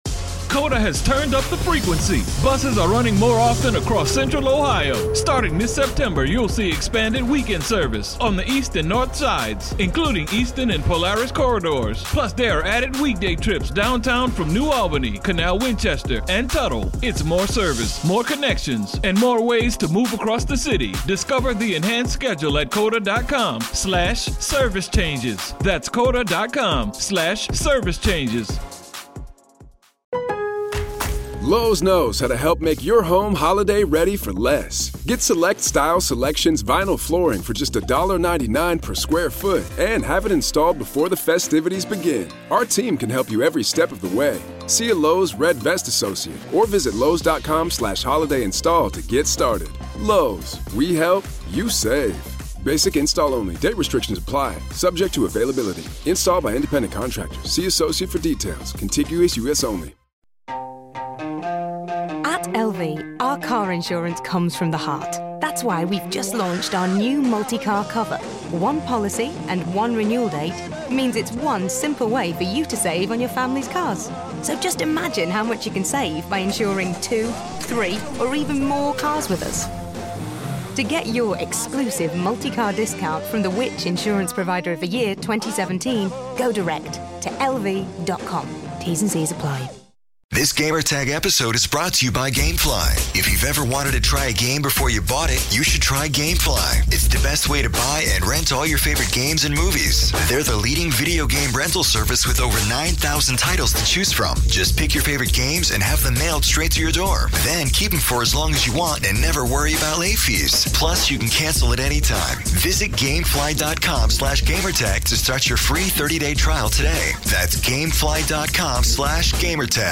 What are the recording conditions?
Presentation about Halo Wars 2 behind closed doors by 343 Industries.